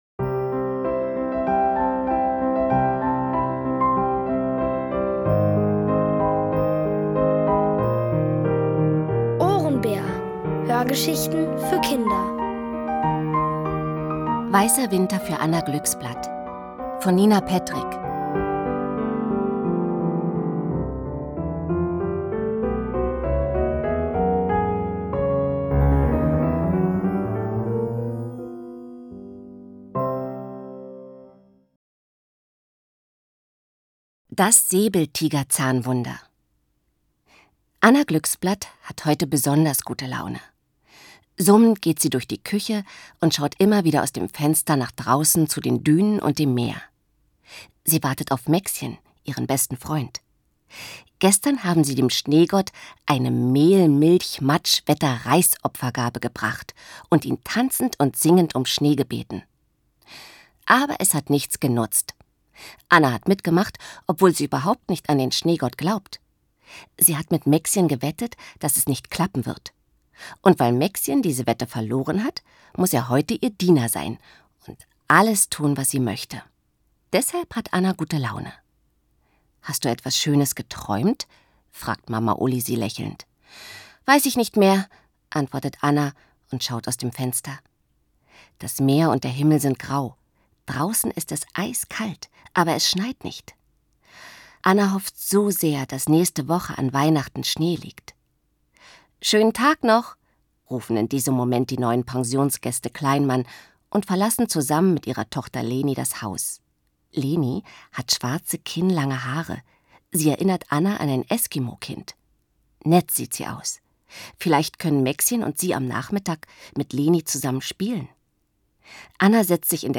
Von Autoren extra für die Reihe geschrieben und von bekannten Schauspielern gelesen.